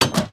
Minecraft Version Minecraft Version 1.21.5 Latest Release | Latest Snapshot 1.21.5 / assets / minecraft / sounds / block / iron_door / close2.ogg Compare With Compare With Latest Release | Latest Snapshot